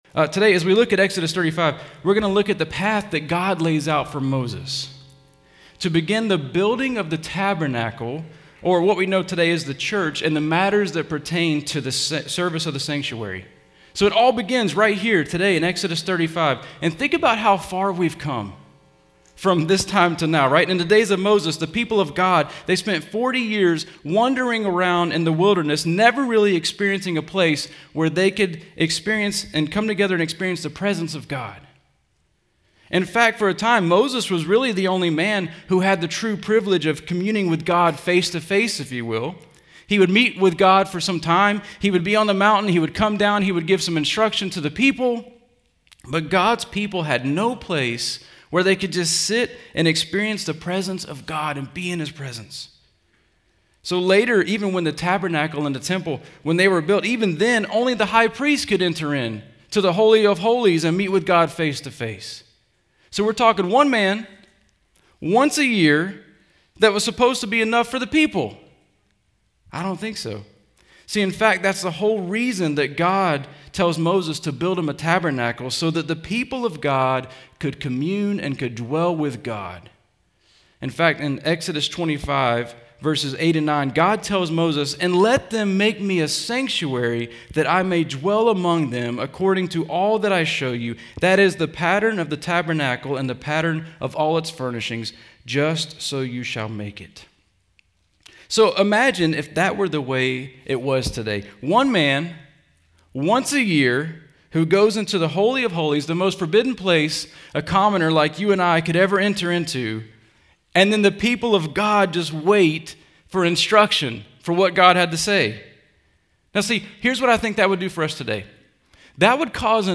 Home » Sermons » Exodus 35: Service of the Sanctuary